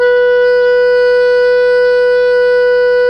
SOP  PP B 3.wav